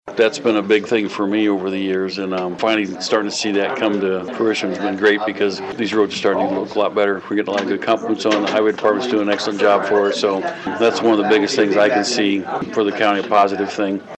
Following a recent meeting, Swick Broadcasting spoke with LaGrange County Commission President Terry Martin who said seeing work done on the roads around the County is something they are very proud of.